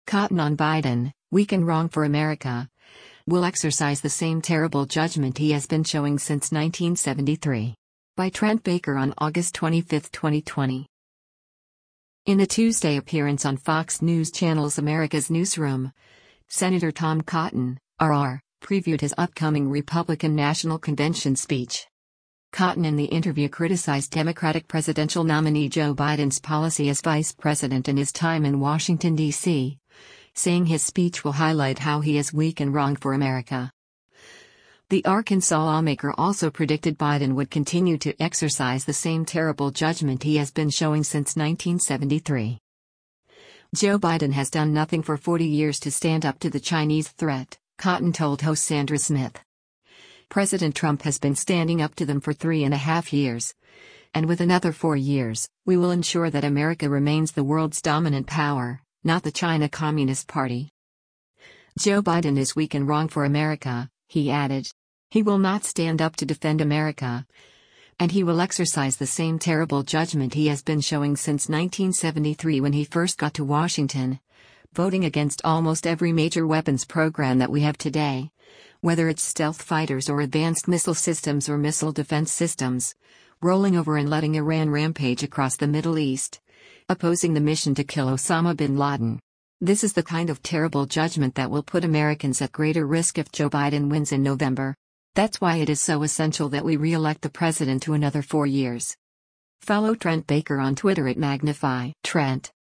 In a Tuesday appearance on Fox News Channel’s “America’s Newsroom,” Sen. Tom Cotton (R-AR) previewed his upcoming Republican National Convention speech.